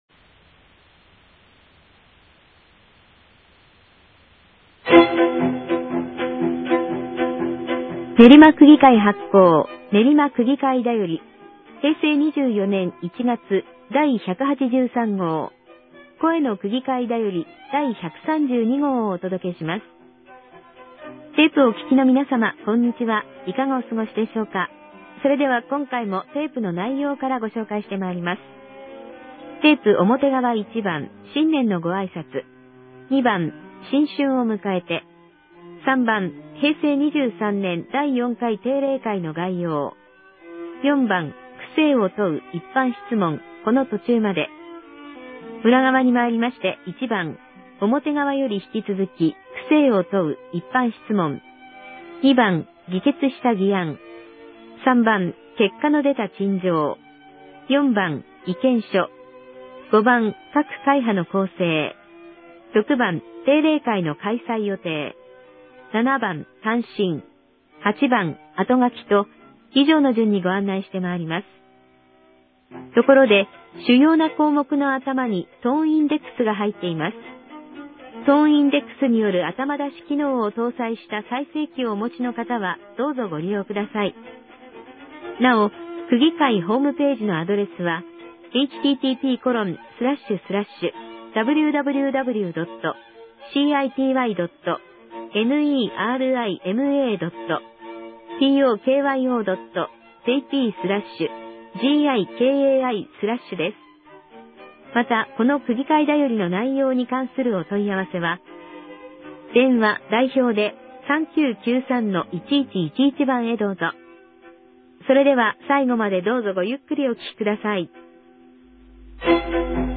練馬区議会では、目の不自由な方のために、カセットテープによる「声の区議会だより」を発行しています。